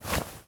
foley_cloth_light_fast_movement_16.wav